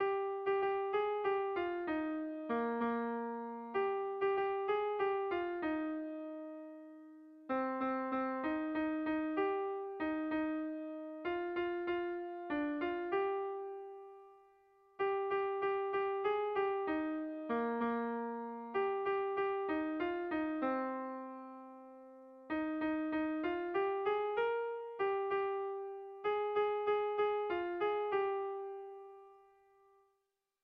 Sentimenduzkoa
Zortziko ertaina (hg) / Lau puntuko ertaina (ip)
A1BA2D